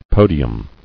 [po·di·um]